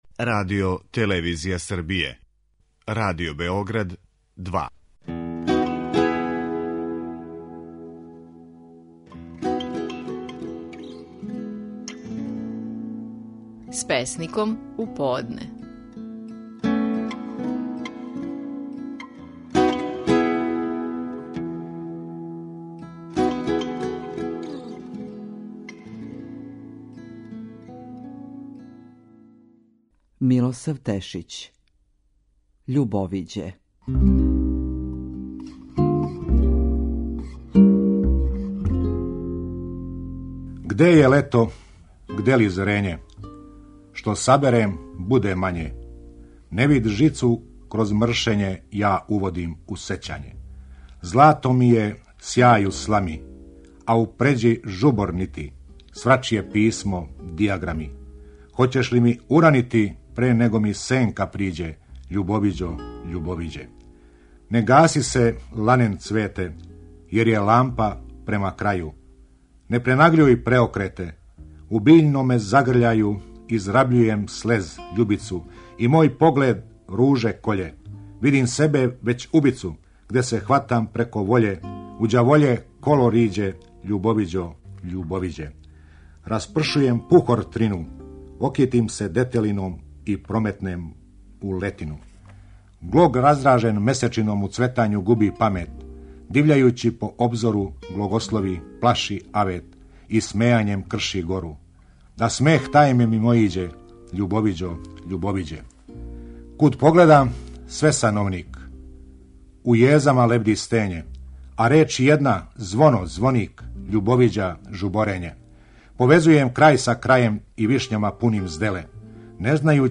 Наши најпознатији песници говоре своје стихове
Слушамо Милосава Тешића и његову песму "Љубовиђе".